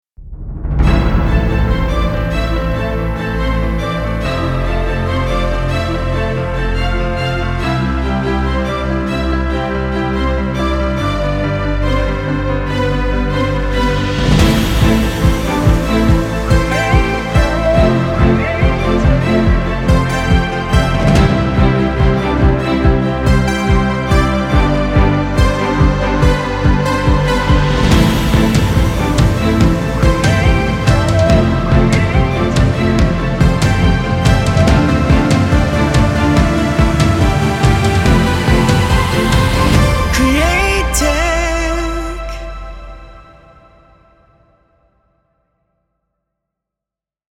SOUND IDENTITY